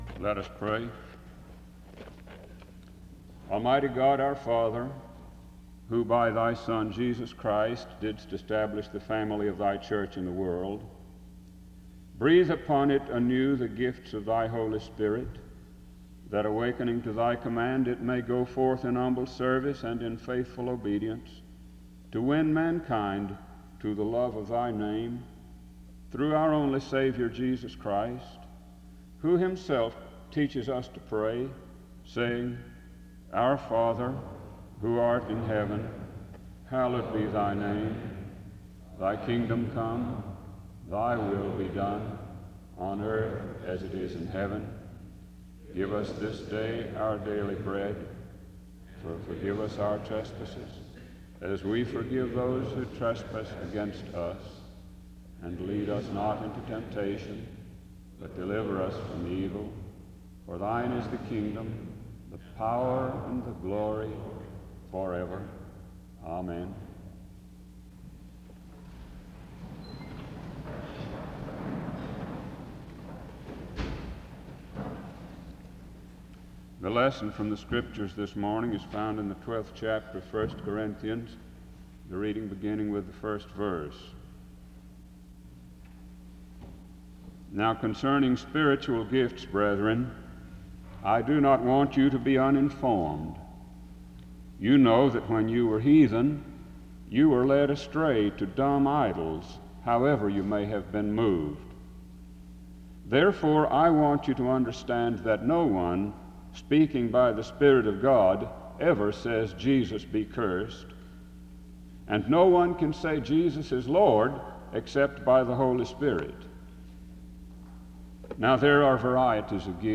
The service begins with prayer from 0:00-1:09. 1 Corinthians 12:1-13 is read from 1:16-3:44. Music plays from 3:55-6:58.
He preaches about spirituality and the role of the Spirit in our lives. The service closes with music from 26:51-27:38.